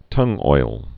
(tŭngoil)